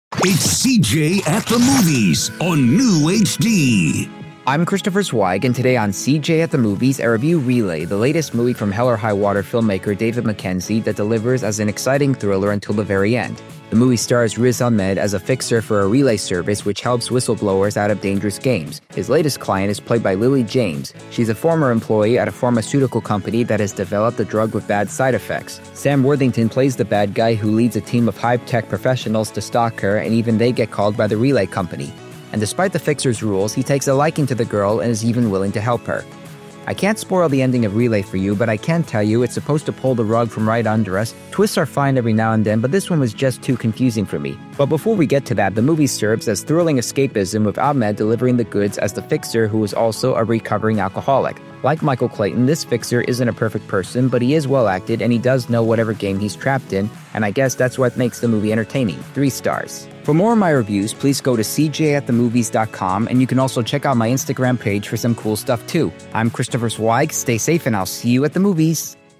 reviews five dangerous movies on the air.